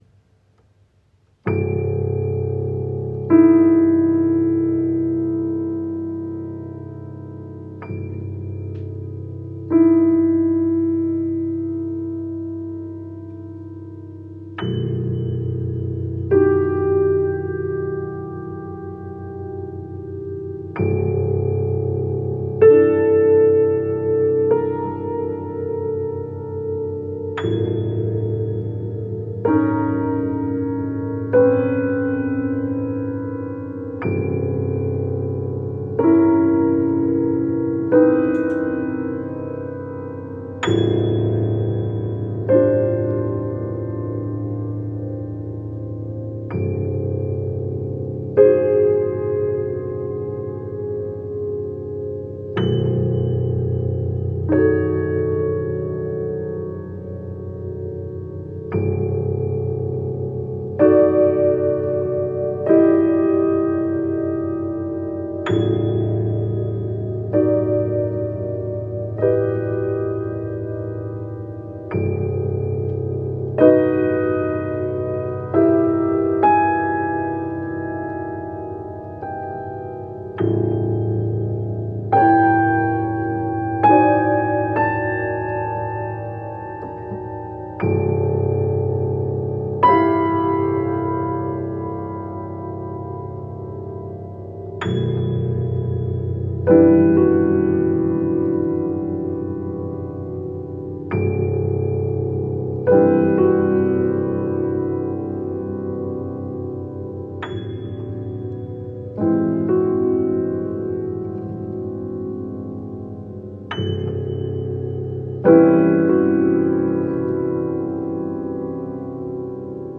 after-rain - Piano Music, Solo Keyboard - Young Composers Music Forum